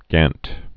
(gănt)